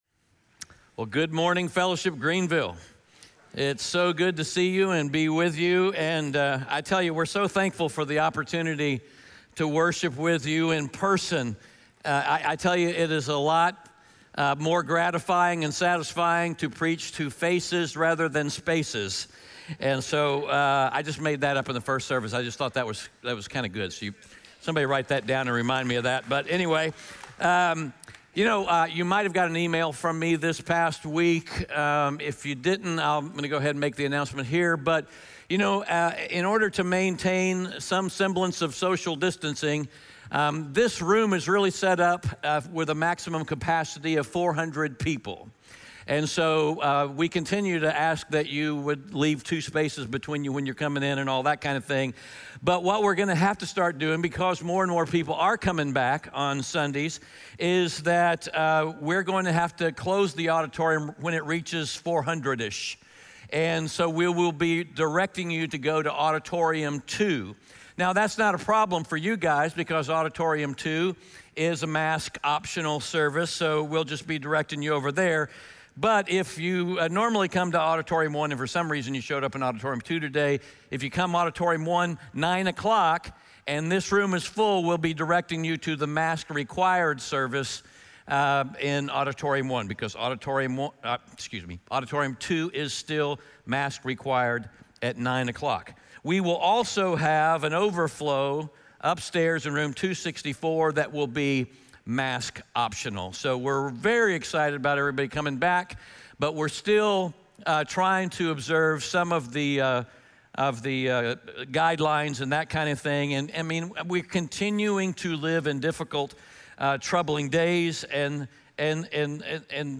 John 14:1-11 Audio Sermon Notes